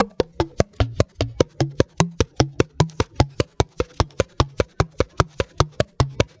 Tiny African Drum Loop (150BPM).wav